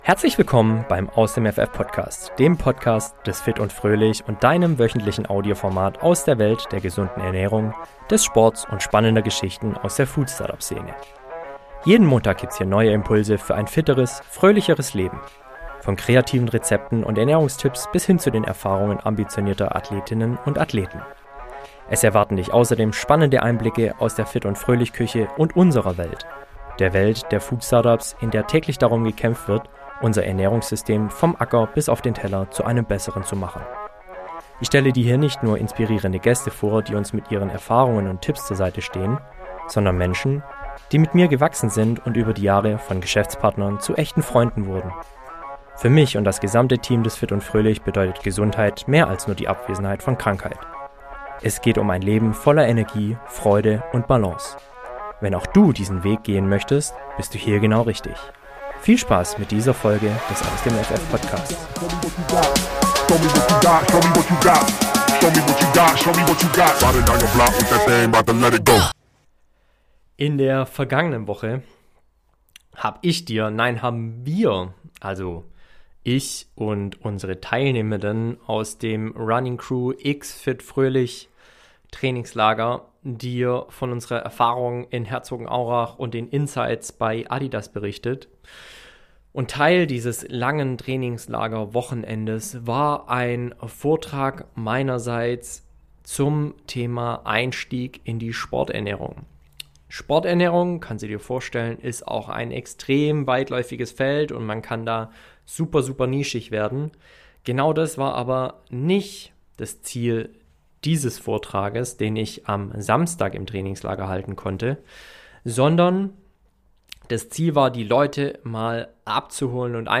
Live: Einführung in die Sporternährung ~ Aus dem ff - der Podcast des fit & fröhlich Podcast